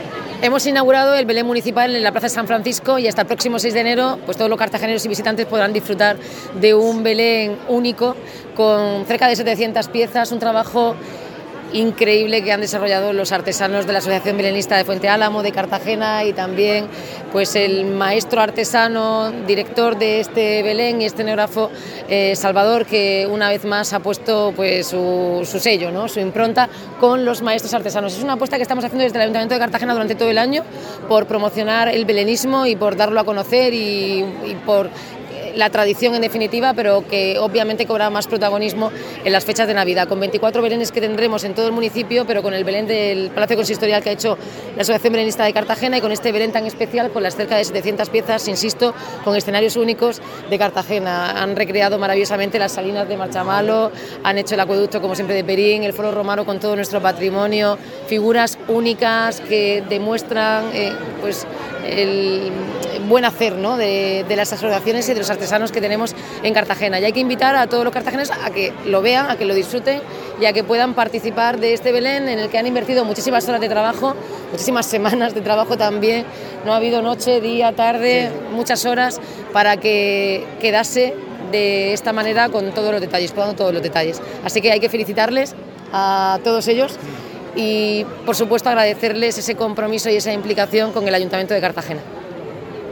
La alcaldesa, Noelia Arroyo, ha presidido este sábado 6 de diciembre la inauguración, que incluía la bendición del Belén y la actuación del coro de niños del CEIP Virgen del Carmen.